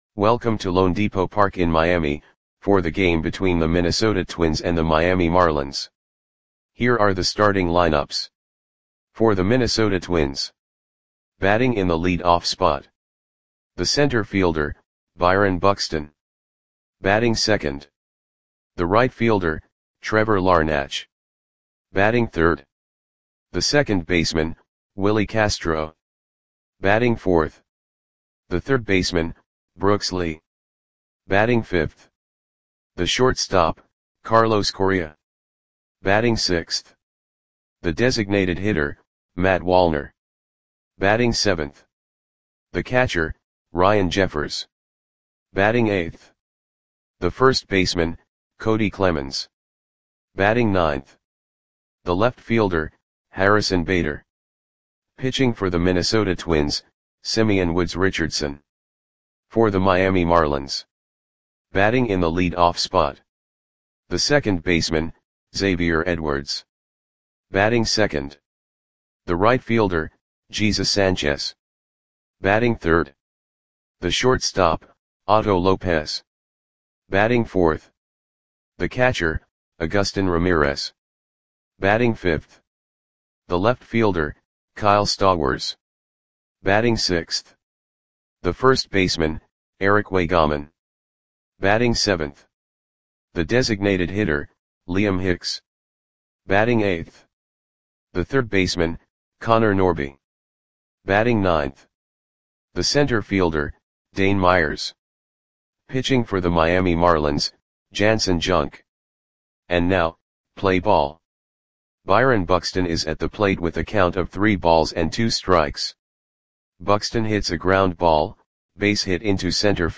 Audio Play-by-Play for Miami Marlins on July 2, 2025
Click the button below to listen to the audio play-by-play.